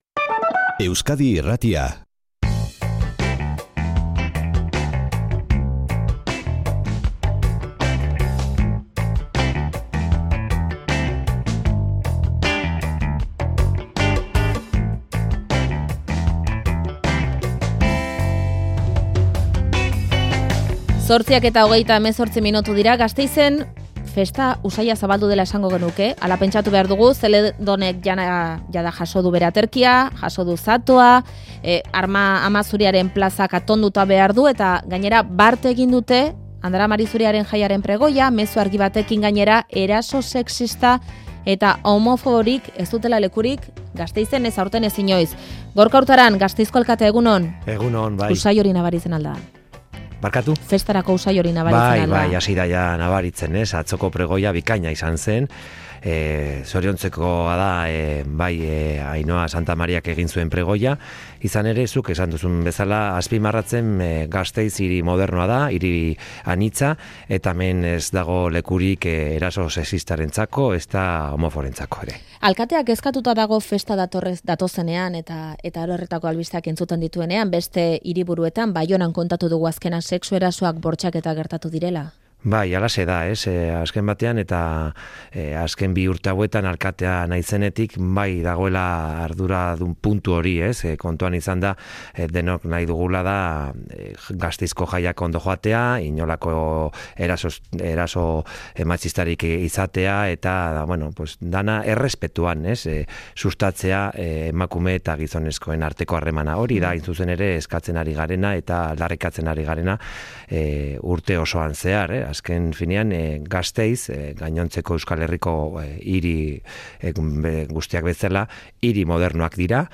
Gorka Urtaran, Gasteizko alkatea, Euskadi Irratiko Faktorian
Ama Zuriaren jaien bezperan, Faktorian albistegian izan dugu Gorka Urtaran Gasteizko alkatea